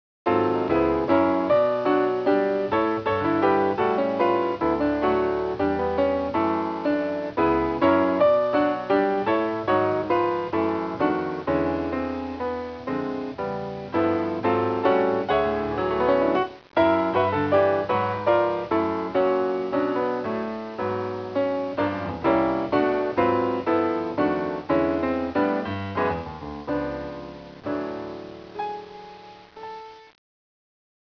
It proved to be a fortuitous move, for there he met two musicians he immediately recognized could become the nucleus of an exciting jazz trio.
gDanny Boyh is one of the two folk songs in the collection, (one from the West and one from the East), this western one given a straight ahead jazz treatment.